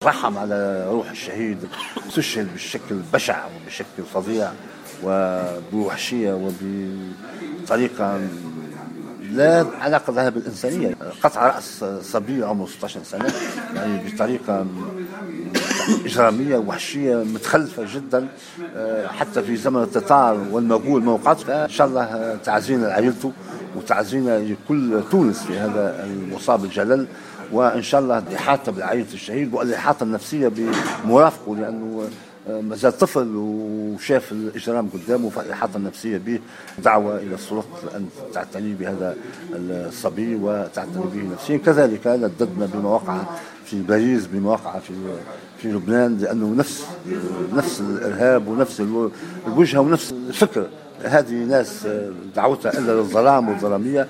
وقال المسؤول النقابي في تصريح لـ "الجوهرة اف أم" على هامش اجتماع نقابي في المنستير، إنه من الضروري الإحاطة النفسية بمرافق الشهيد الذي لا يزال طفلا وعلى السلطات التدخل للعناية به.